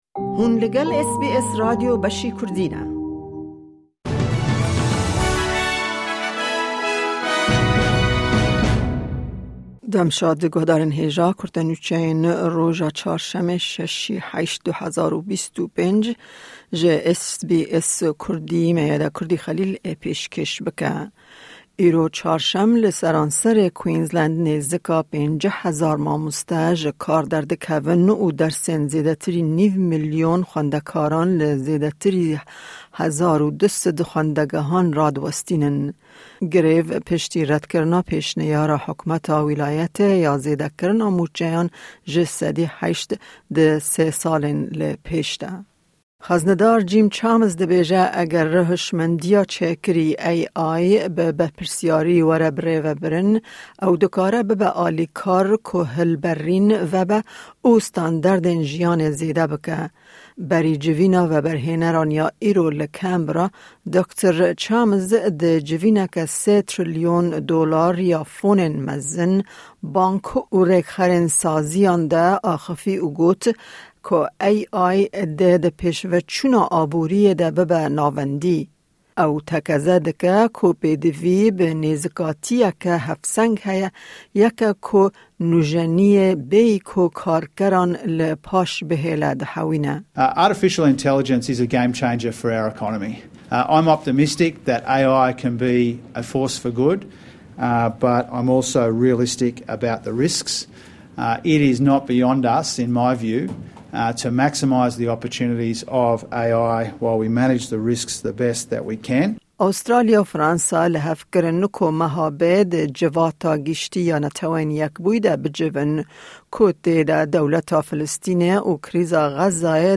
Kurte Nûçeyên roja Çarşemê, 6î Tebaxa 2025